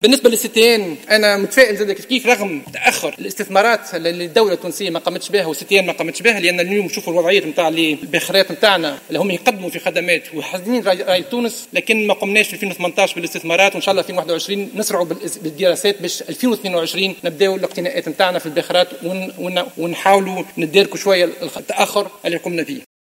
وعبر شقشوق، في إجابته على أسئلة عدد من نواب الشعب خلال جلسة عامة عقدها البرلمان لمناقشة مهمة وزارة النقل واللوجستيك، عن تفائله بالنسبة لمستقبل الـ CTN على الرغم من هذه الاخيرة تأخّرت في القيام بالاستثمارات اللازمة.